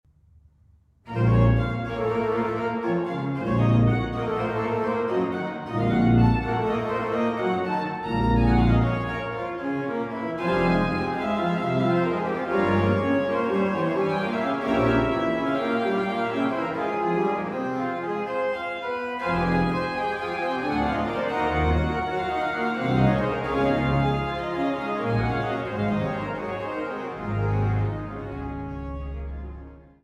Fuge A-Dur